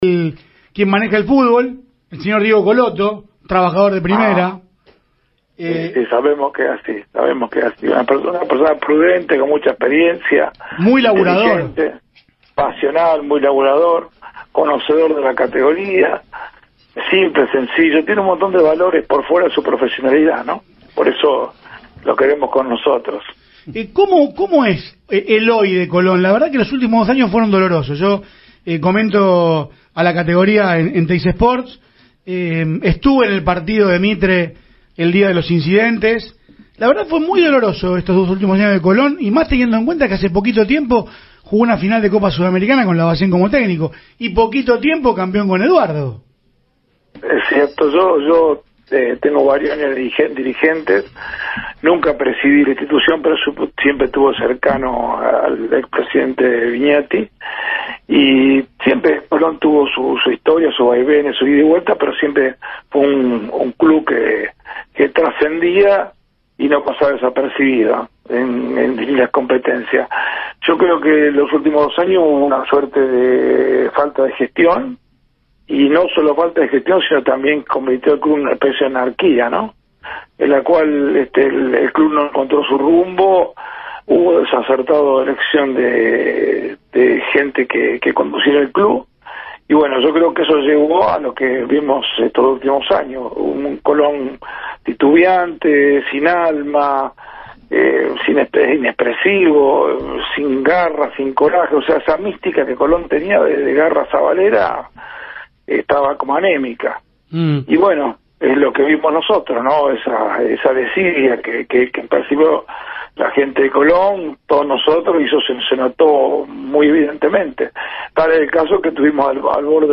una nota con el programa radial Mundo Ascenso (AM 910)